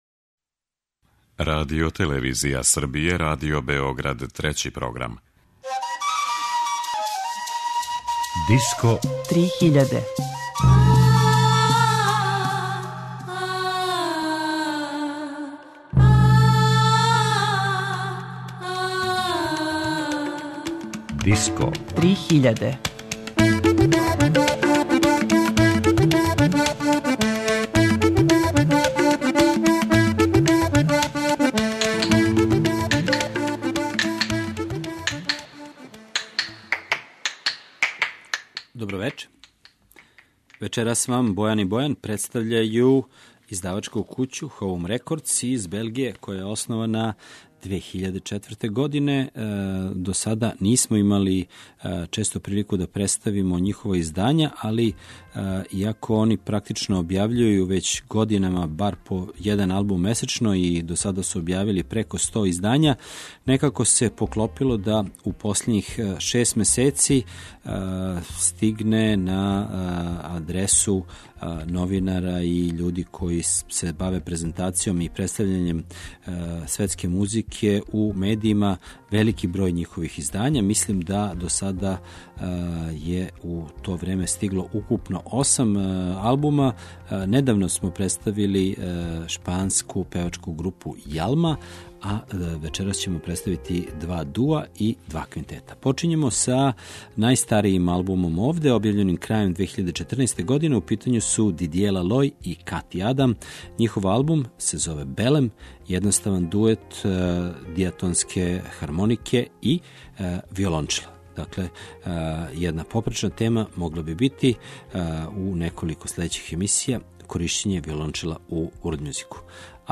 Кроз примере неколико албума из њихове продукције, представићемо ову издавачку кућу. Током вечерашње емисије ћете слушати два дуа и два квинтета.
world music